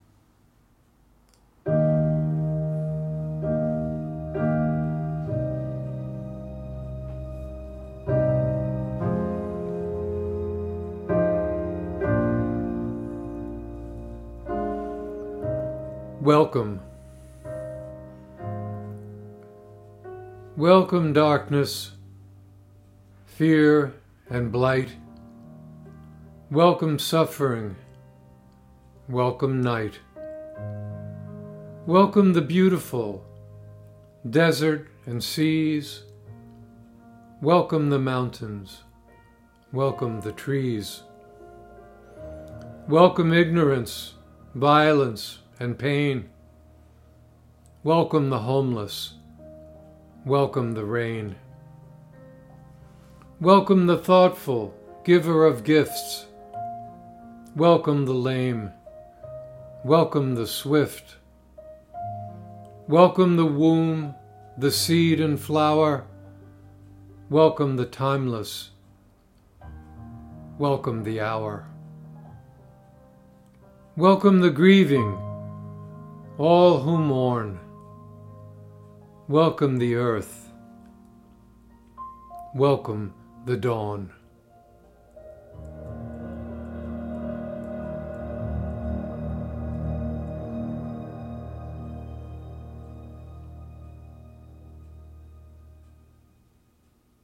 Reading
with music